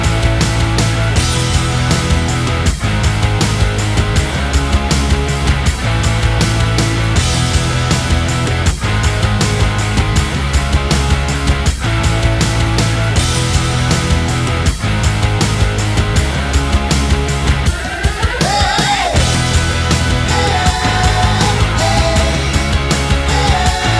No Guitars Pop (2010s) 3:29 Buy £1.50